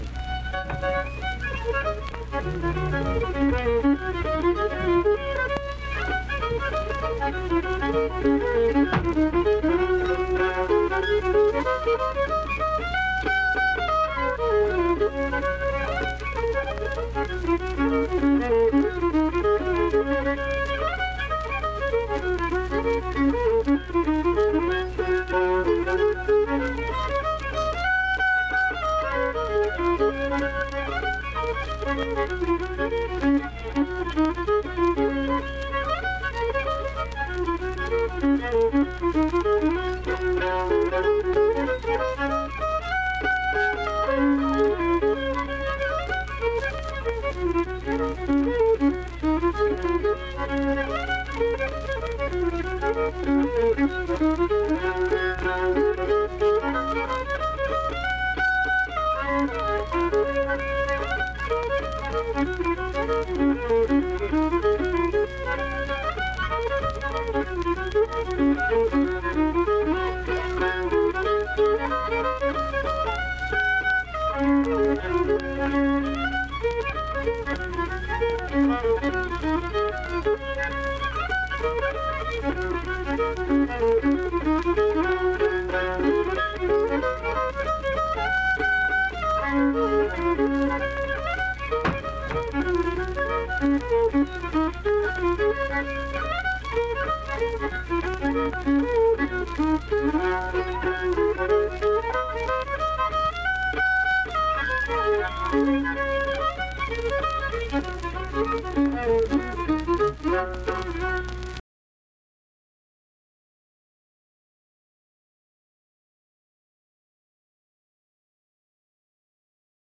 Instrumental fiddle performance.
Instrumental Music
Fiddle
Vienna (W. Va.), Wood County (W. Va.)